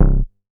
MoogAplex A.WAV